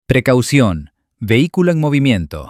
Different sounds to customize the voice buzzer:
Voice Alarm ES official.mp3